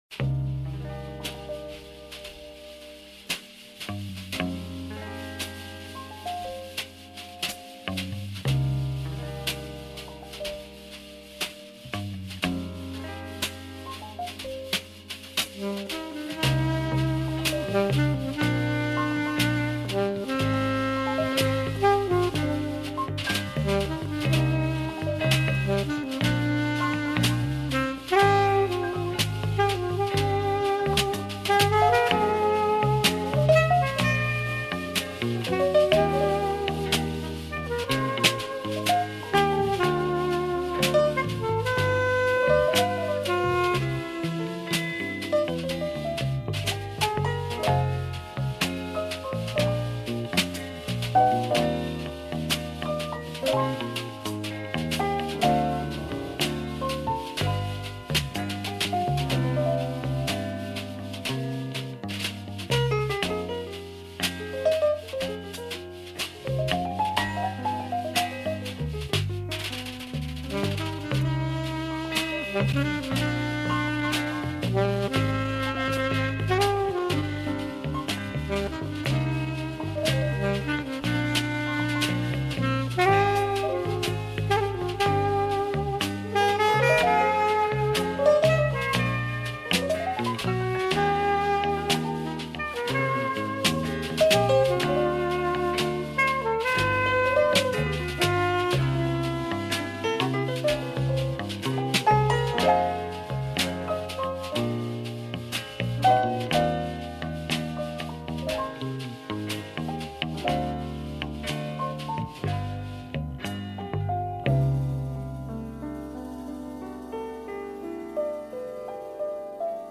С осенним настроением.